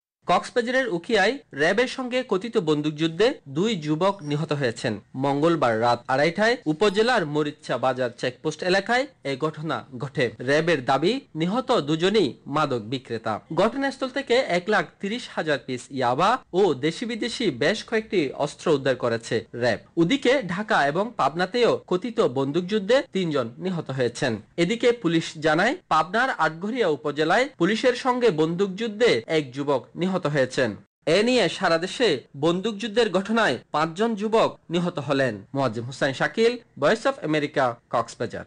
কক্সবাজার থেকে
রিপোর্ট